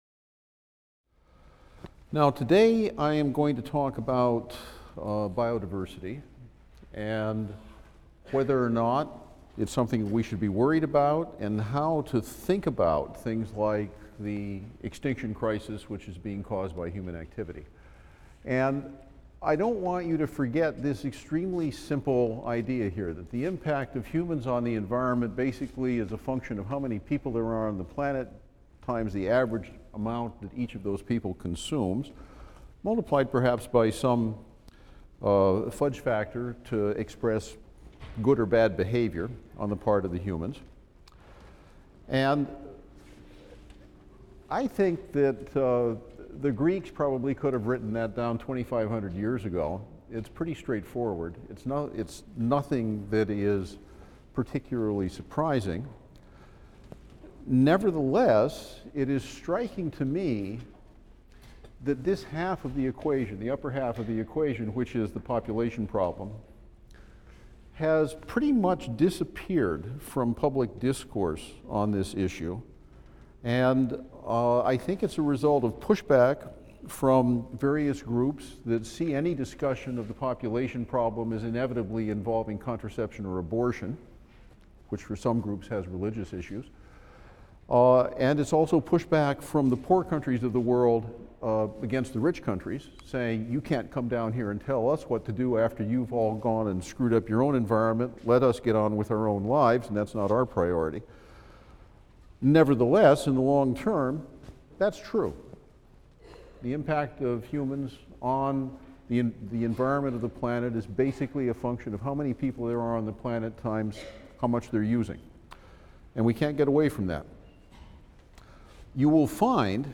E&EB 122 - Lecture 31 - Why So Many Species? The Factors Affecting Biodiversity | Open Yale Courses